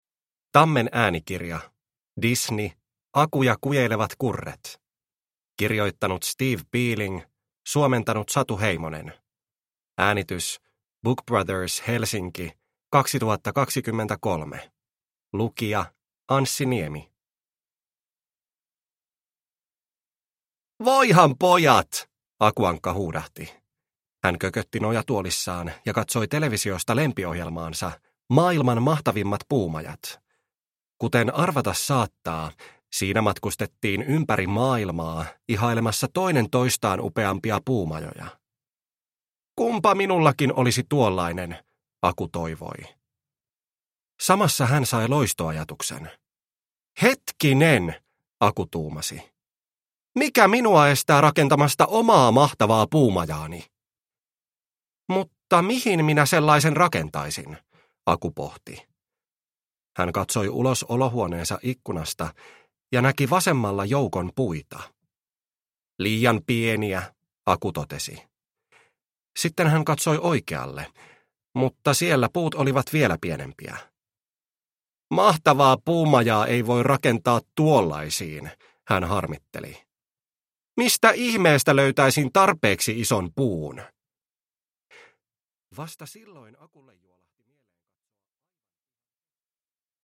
Disney. Aku ja kujeilevat kurret – Ljudbok – Laddas ner